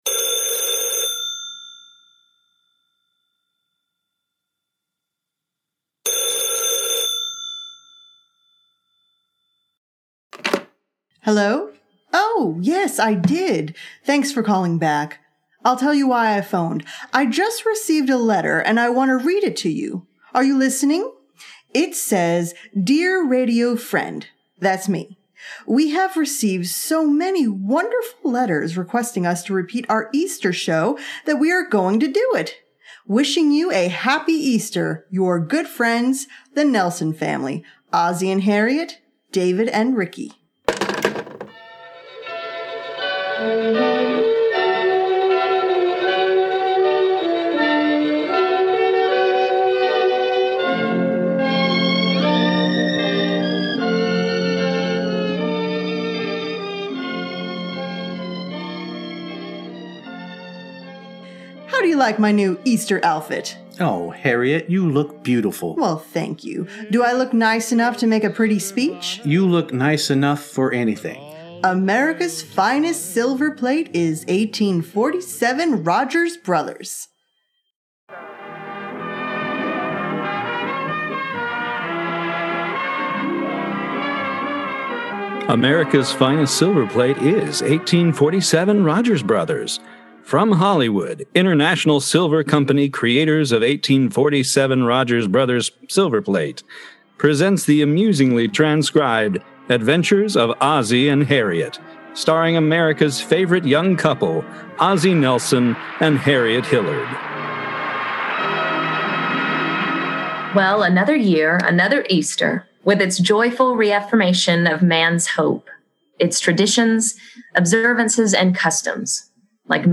After you hunt those eggs and eat those Peeps, enjoy this year's IDS Easter special which features an episode of the OTR series, The Adventures of Ozzie and Harriet, entitled quite conveniently, The Easter Show. It seems the struggle to get up for sunrise services on Easter is an age old predicament.